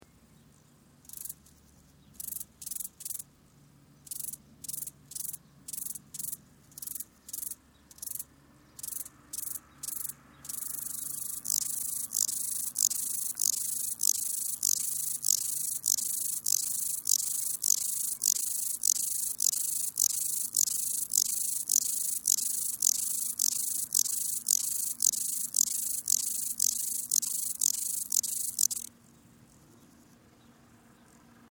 Gebirgsgrashüpfer
Wie der Name bereits sagt, ist der Gebirgsgrashüpfer eine Heuschreckenart, die vor allem in den höheren Lagen zu finden ist. Der Gesang des Gebirgsgrashüpfers ist auffällig und kann die Geräuschkulisse einer Alpenwiese dominieren.
Hören Sie sich hier den Gesang des Gebirgsgrashüpfers an.
Gebirgsgrashüpfer.mp3